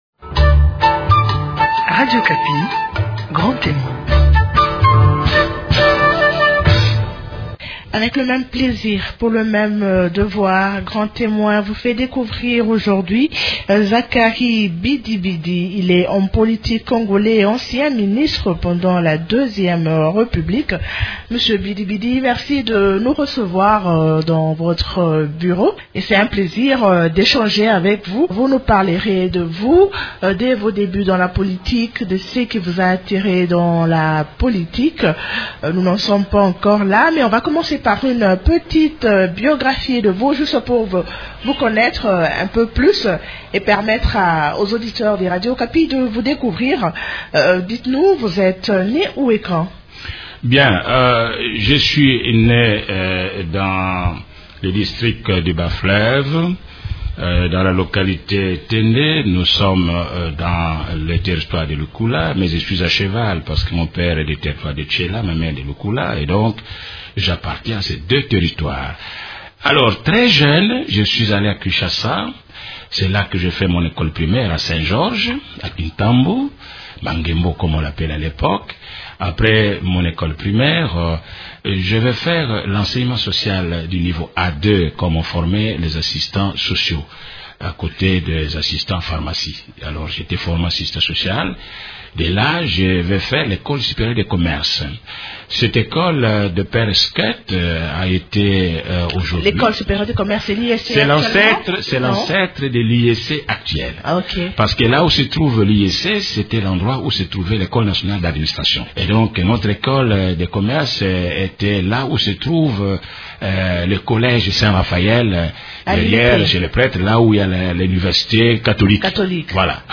L’invité de grand témoin de ce dimanche 7 octobre 2012 est Zacharie BidiBidi. Il est homme politique congolais, Co-fondateur du Parti politique Parti démocrate socialiste chrétien (PDSC) de Joseph Ileo .Il a été aussi ancien ministre pendant la deuxième république.